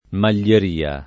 maglieria [ mal’l’er & a ] s. f.